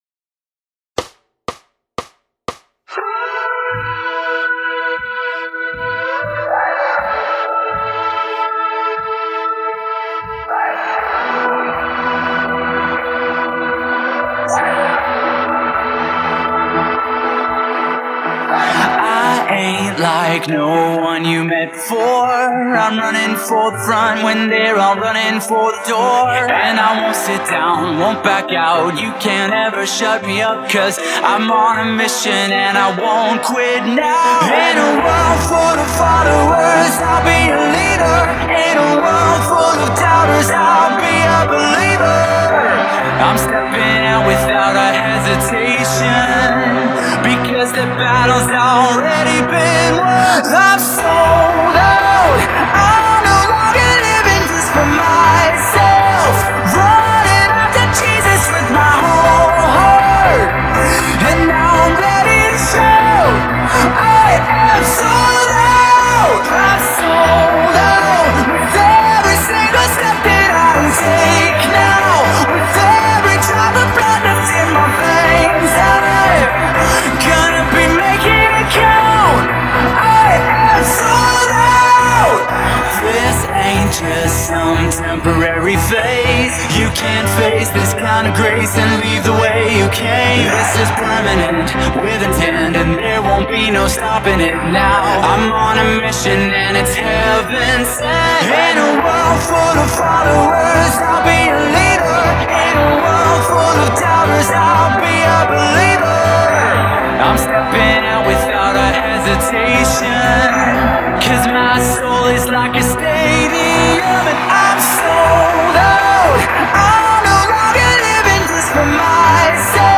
每分钟120拍